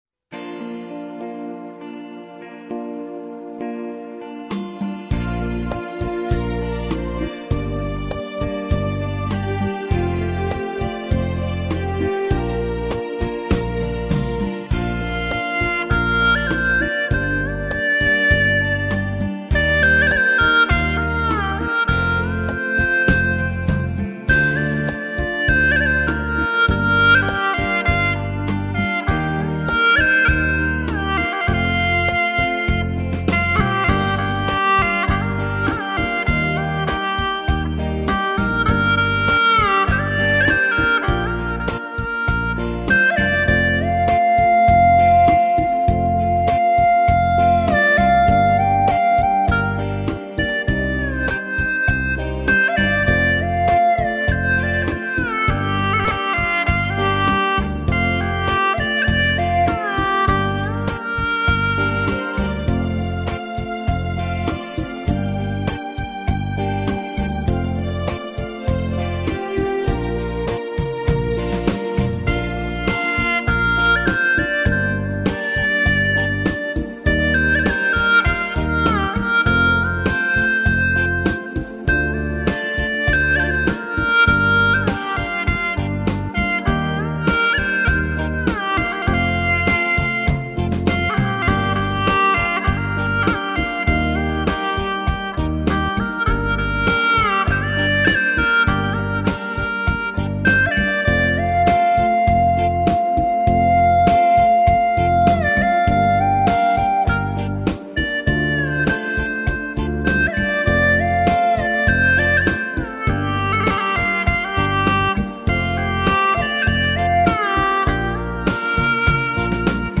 调式 : 降B 曲类 : 红歌
旋律舒展流畅，线条柔婉，感情细腻，具有新颖的气息及清新的民歌风味